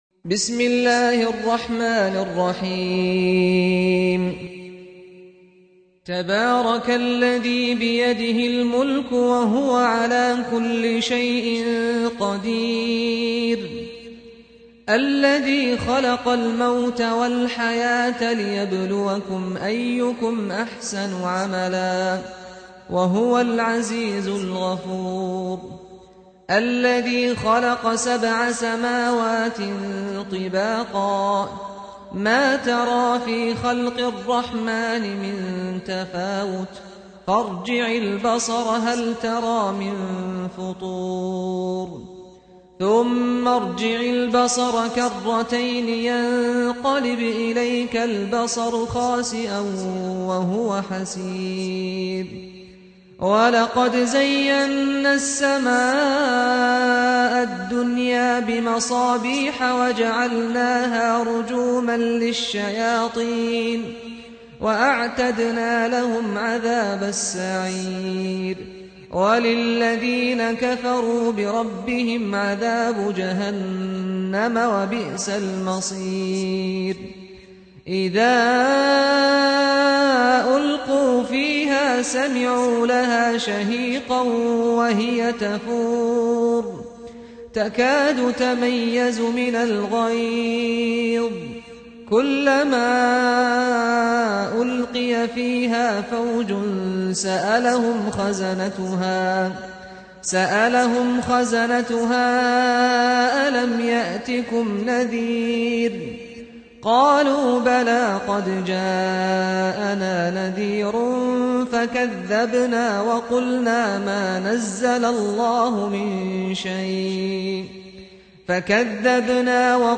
سُورَةُ المُلۡكِ بصوت الشيخ سعد الغامدي